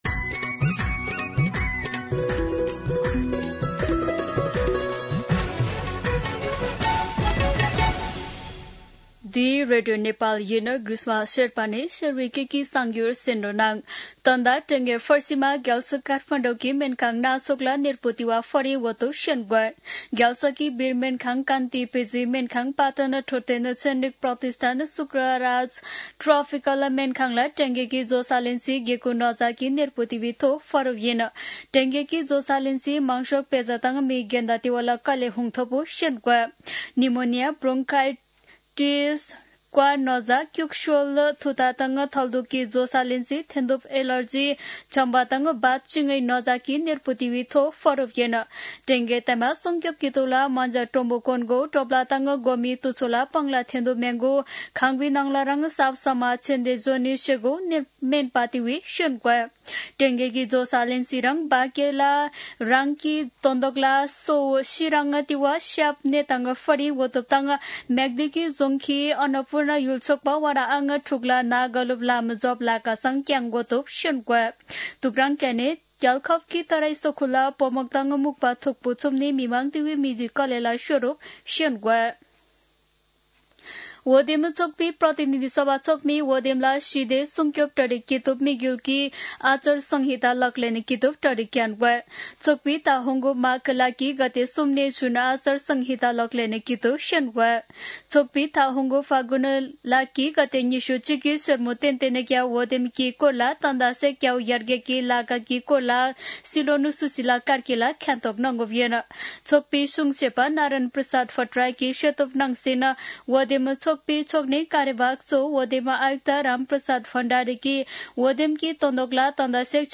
शेर्पा भाषाको समाचार : २६ पुष , २०८२
Sherpa-News-1.mp3